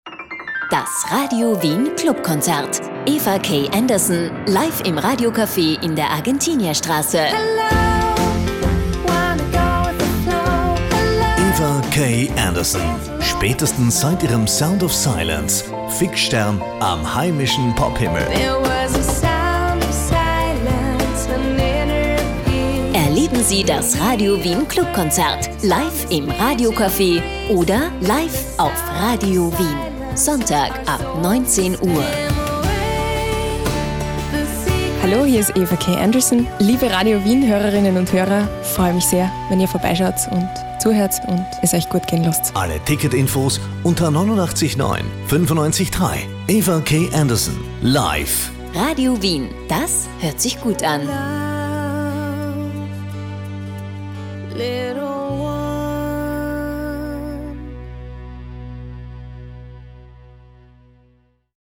ausverkaufte unplugged Live-Präsentation
Hier der gelungene Konzerttrailer zum Reinhören Weiterlesen